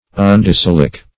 Search Result for " undecylic" : The Collaborative International Dictionary of English v.0.48: Undecylic \Un`de*cyl"ic\, a. (Chem.)